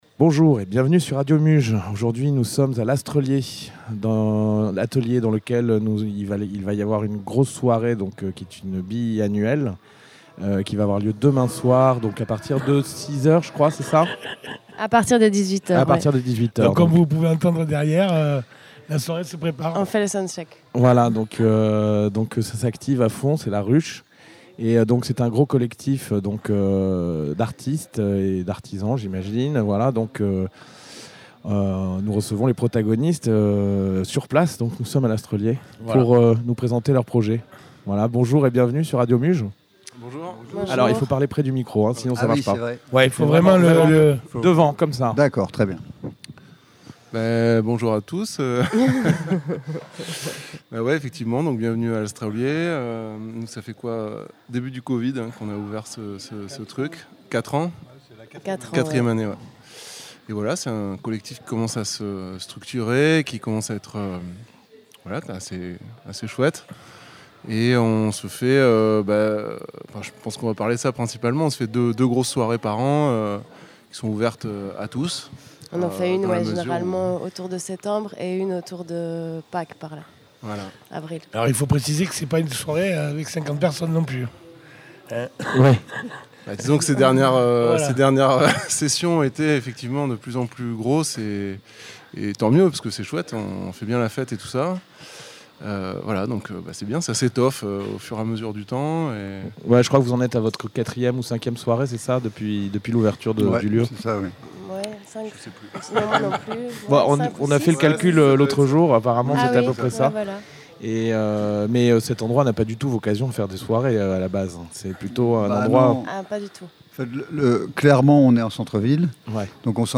ITW de l'équipe de l'Astrelier réalisée par Radio Muge sur place le Vendredi 6 Octobre.